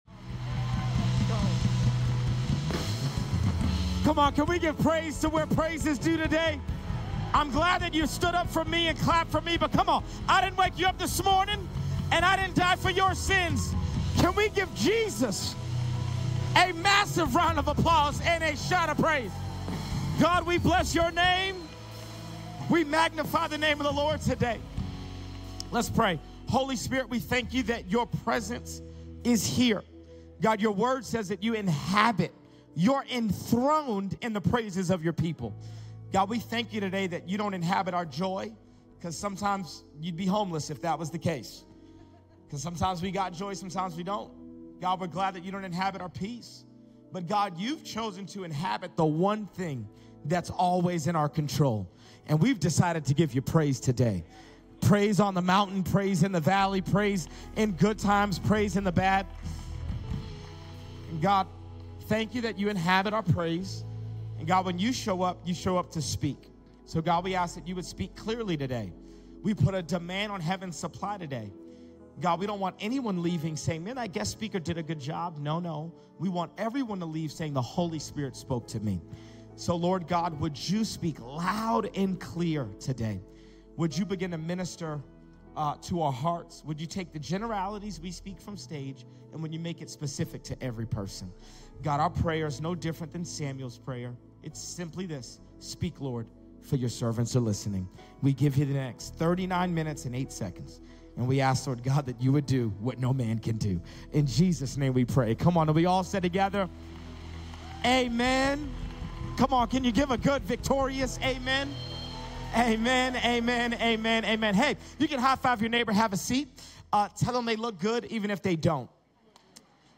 The series of guest speakers at Citizen Church in Albuquerque, New Mexico.